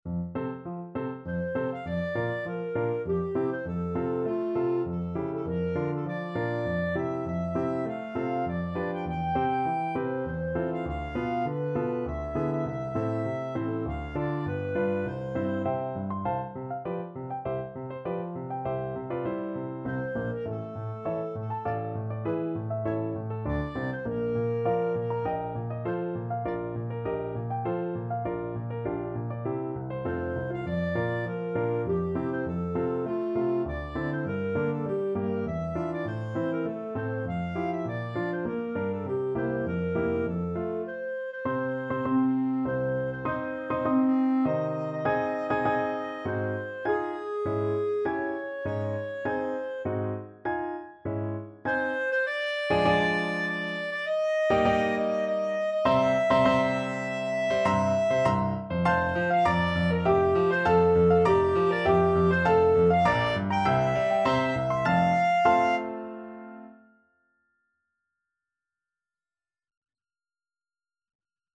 Clarinet
F major (Sounding Pitch) G major (Clarinet in Bb) (View more F major Music for Clarinet )
3/4 (View more 3/4 Music)
~ = 100 Tempo di Menuetto
Classical (View more Classical Clarinet Music)